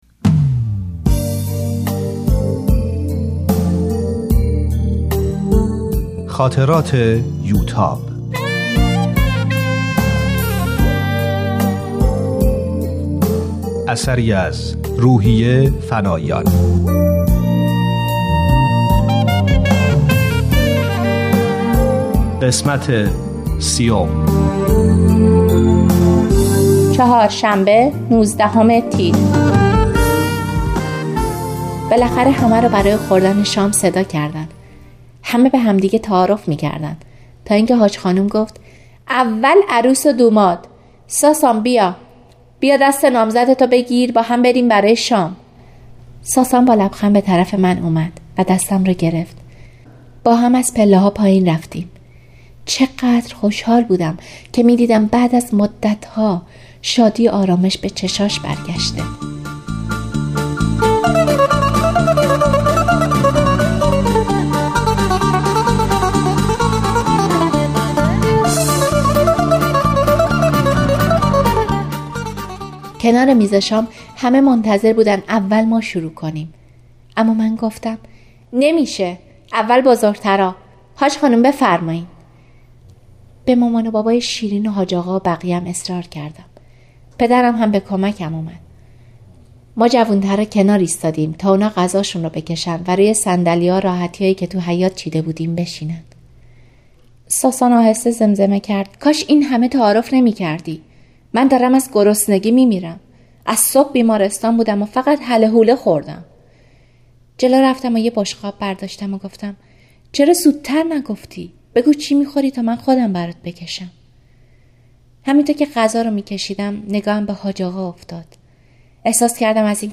کتاب صوتی خاطرات یوتاب | تعالیم و عقاید آئین بهائی